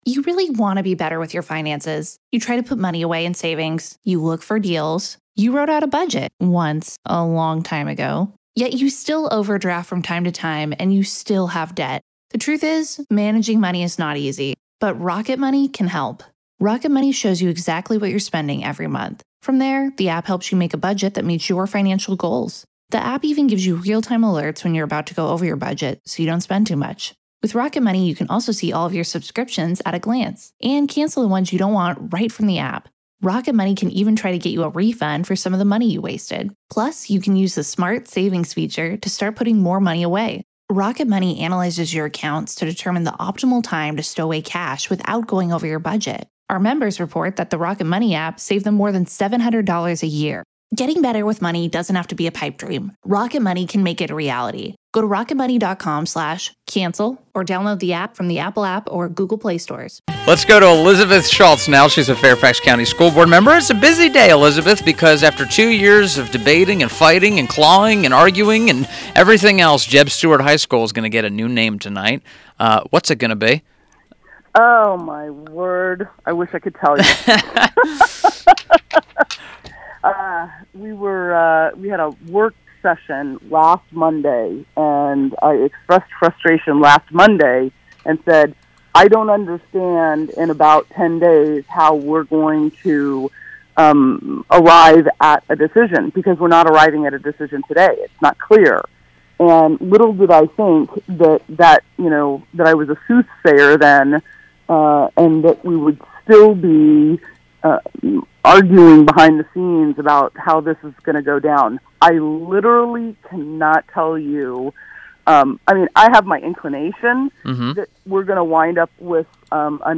WMAL Interview - ELIZABETH SCHULTZ - 10.26.17